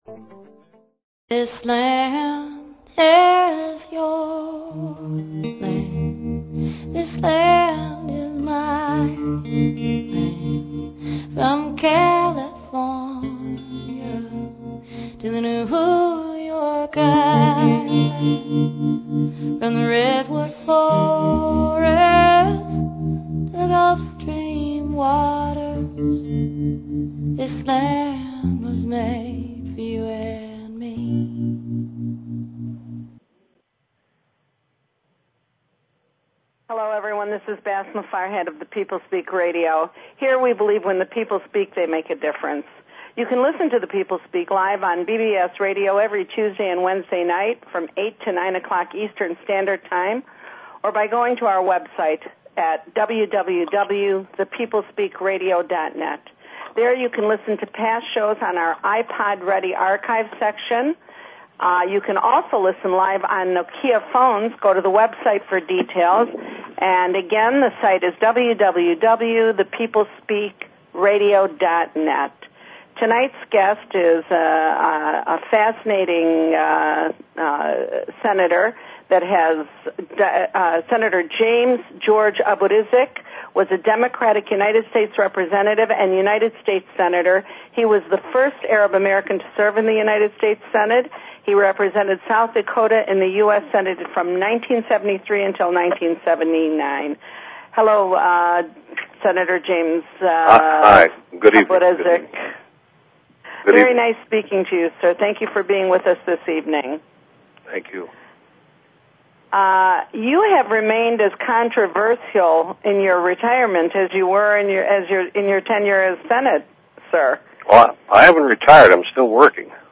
Guest, James Abourezk